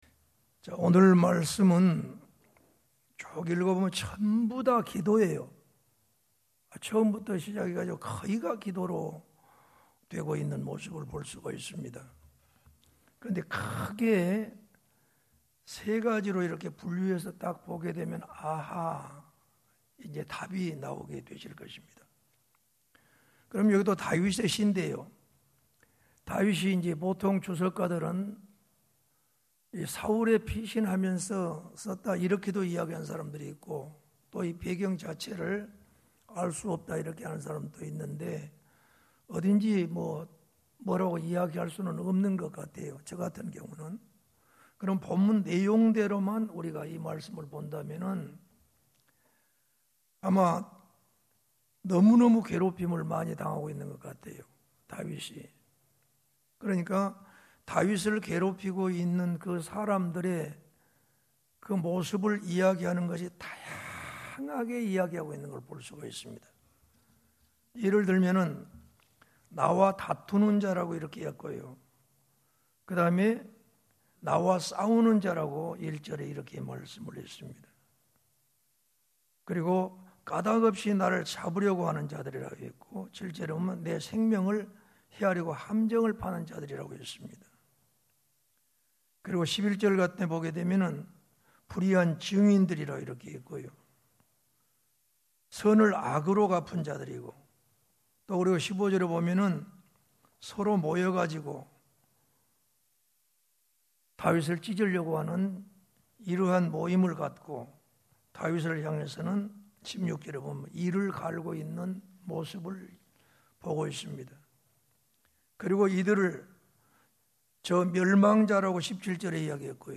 [시편 35편] 2020년 8월 12일(수요밤) > 말씀설교 | 창조교회